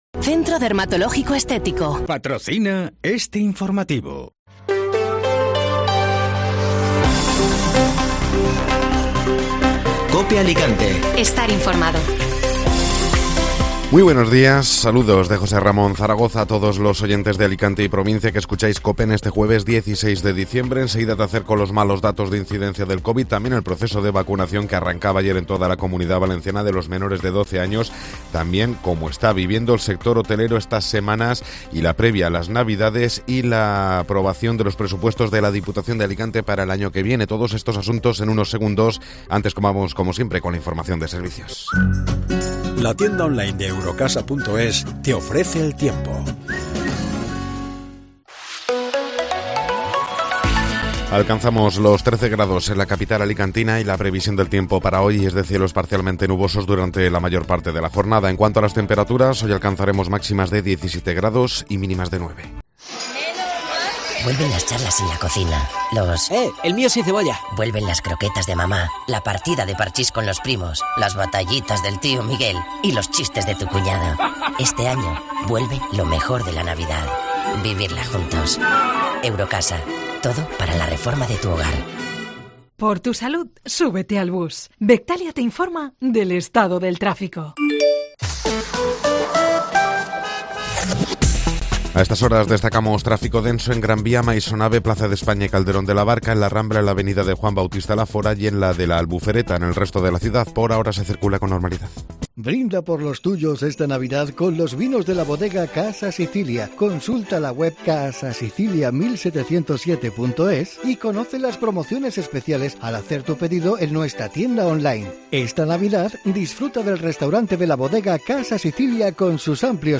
Informativo Matinal (Jueves 16 de Diciembre)